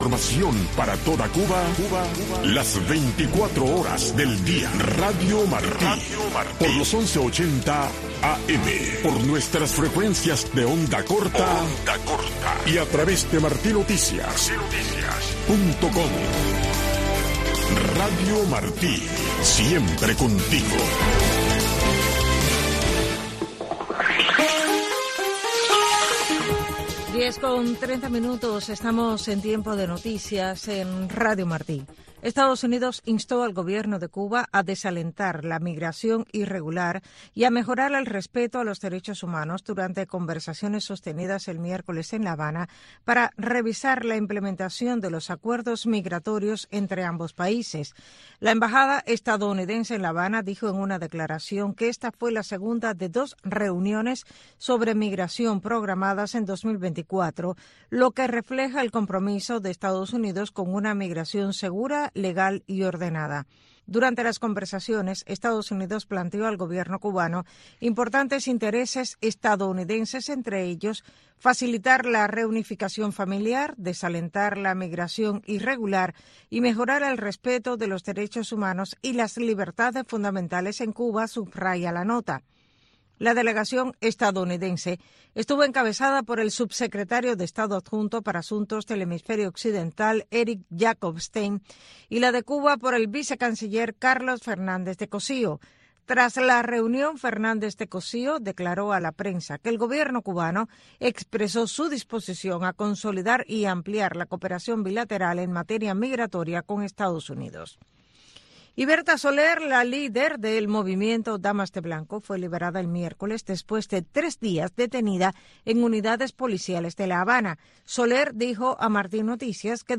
Un espacio radial que va más allá de los nuevos avances de la ciencia y la tecnología, pensado para los jóvenes dentro de la isla que emplean las nuevas tecnologías para dar solución a sus necesidades cotidianas.